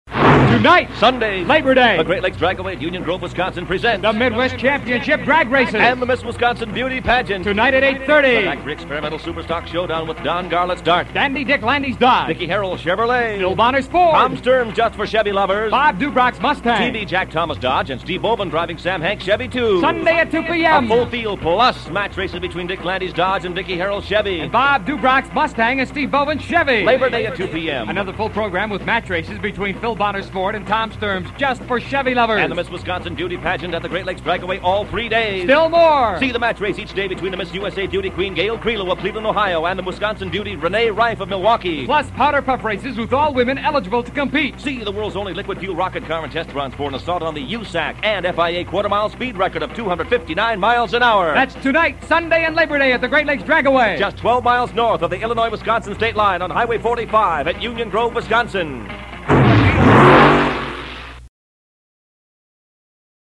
Drag Strip Radio Spots